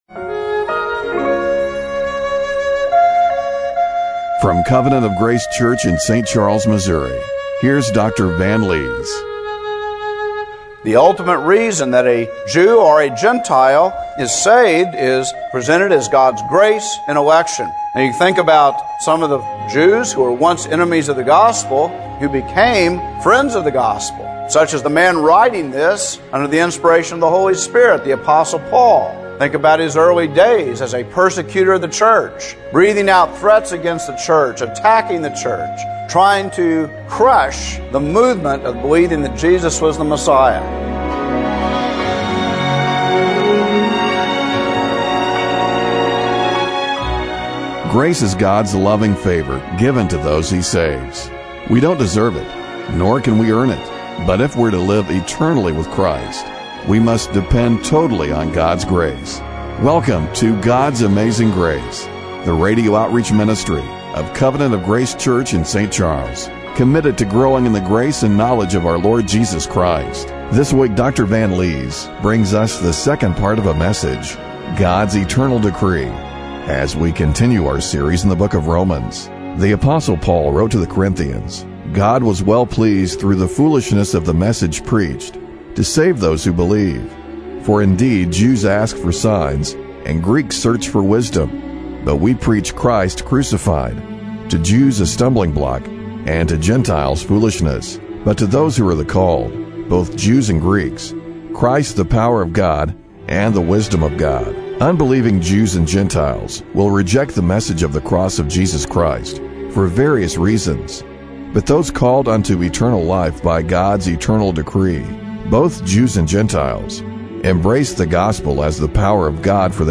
Exposition of Romans Passage: Romans 11:28-36 Service Type: Radio Broadcast Have you embraced the Gospel as the power of God for your salvation?